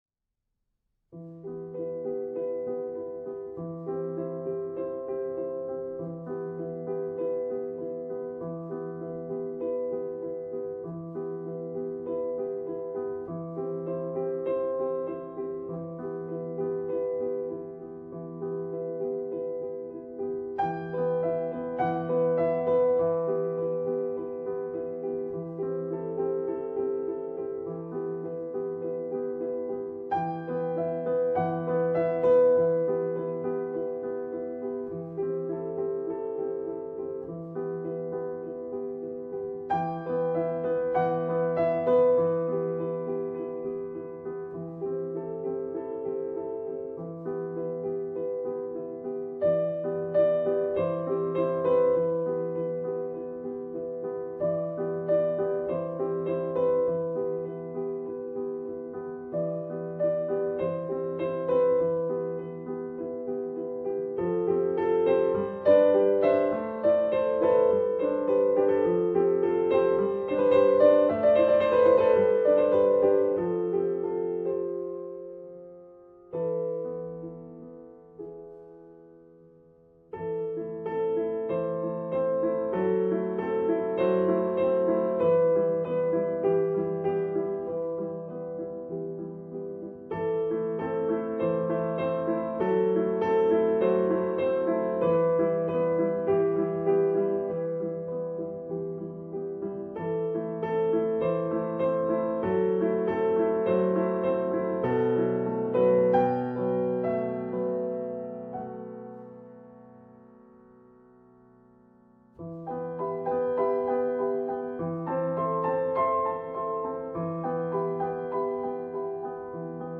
leicht arrangiert